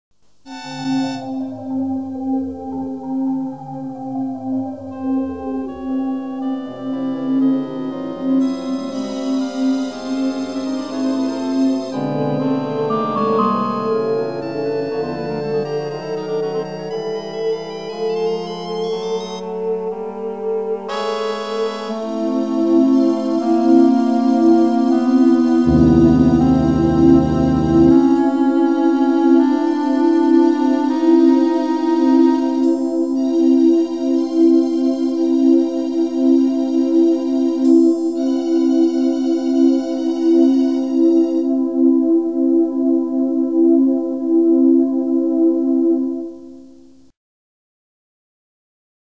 Daraus ergibt sich eine rhythmische Struktur eines 4/4 Taktes, die als horizontales Raster über die gesamten Fassaden gelegt wird.
Dies ergibt eine temperierte Rasterung von 4 Oktaven und zwei Halbtönen.